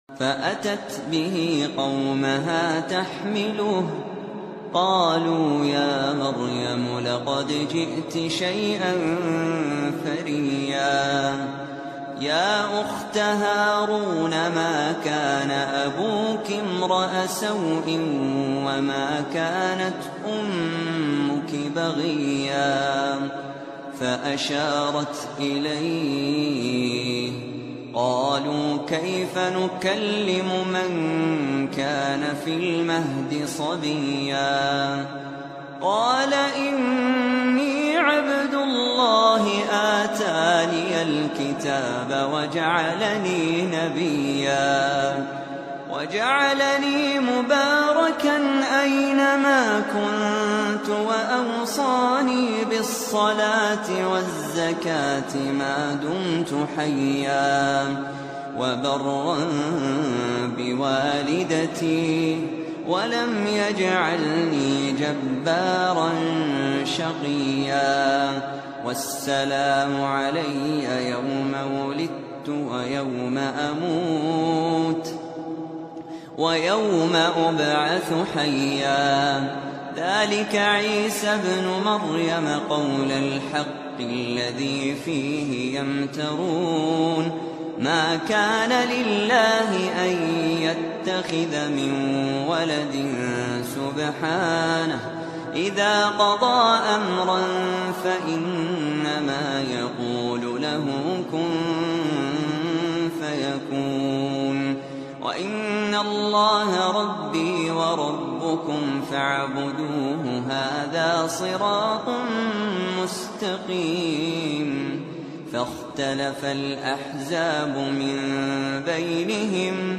تلاوة من مريم